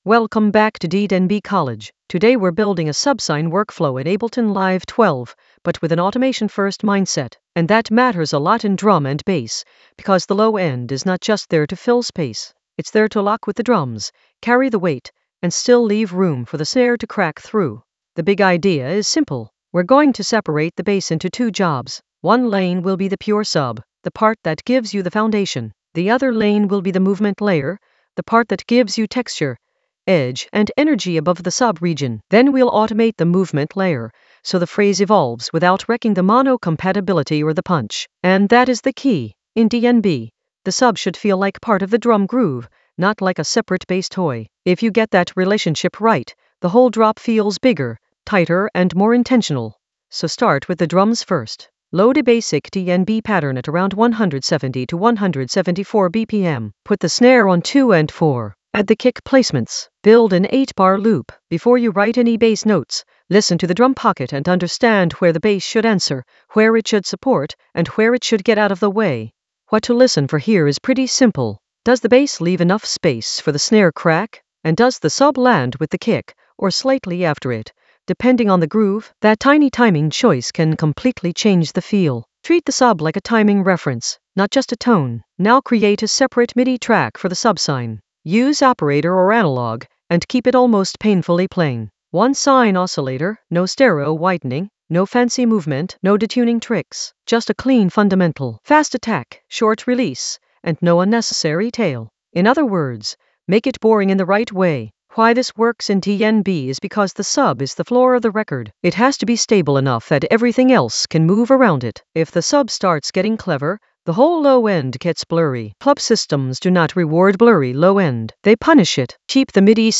An AI-generated intermediate Ableton lesson focused on Layer a subsine workflow with automation-first workflow in Ableton Live 12 in the Drums area of drum and bass production.
Narrated lesson audio
The voice track includes the tutorial plus extra teacher commentary.